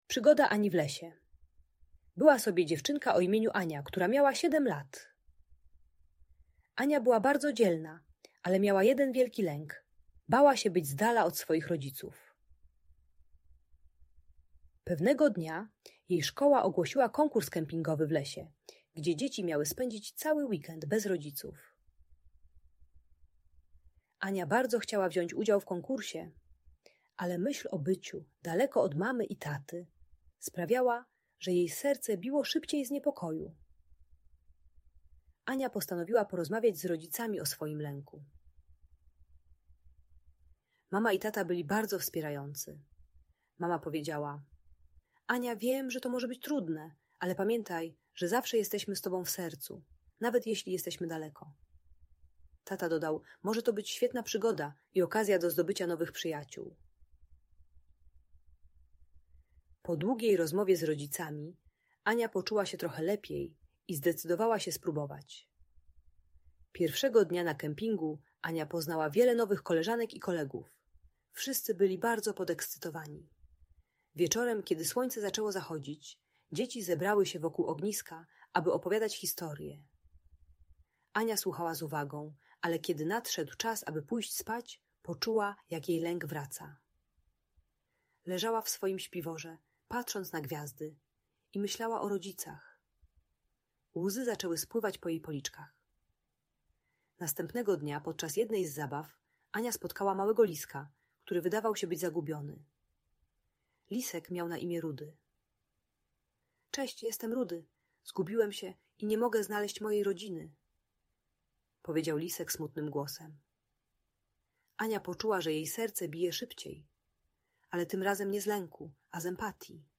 Przygoda Ani w Lesie - Audiobajka